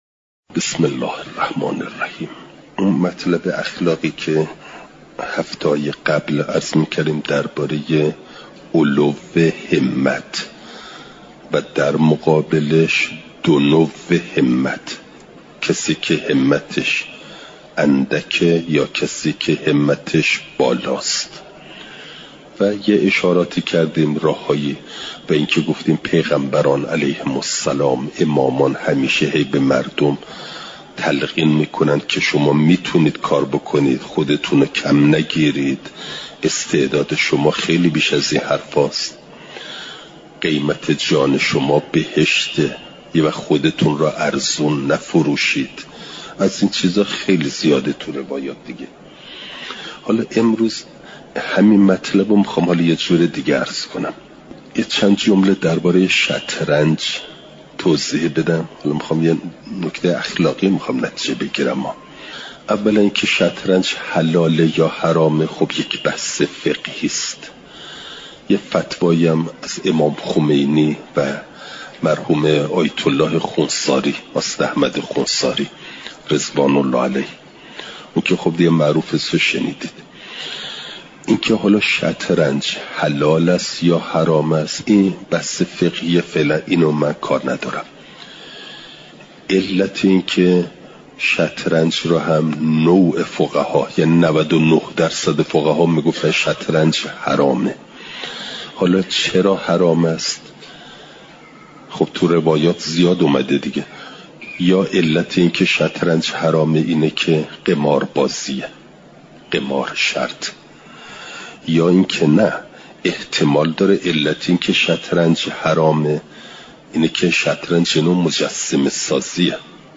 بیانات اخلاقی
چهارشنبه ۲۴ اردیبهشت ماه ۱۴۰۴، حرم مطهر حضرت معصومه سلام ﷲ علیها